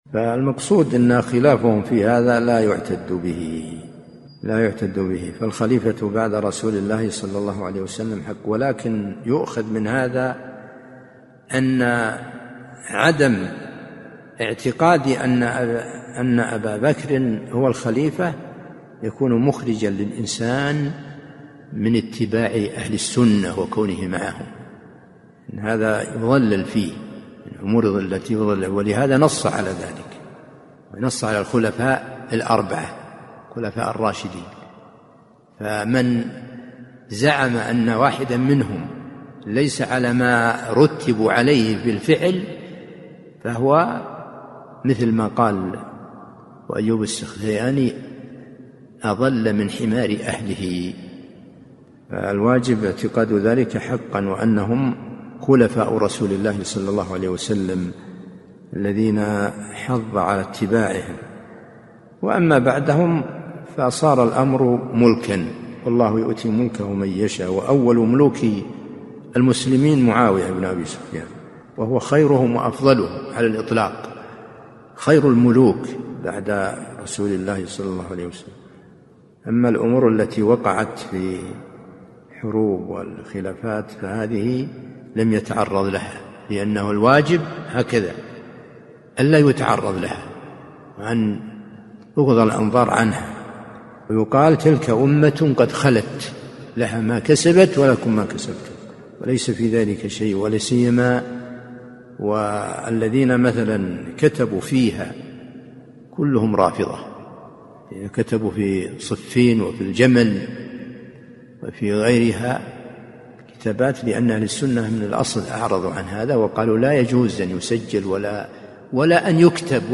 سلسلة محاضرات صوتية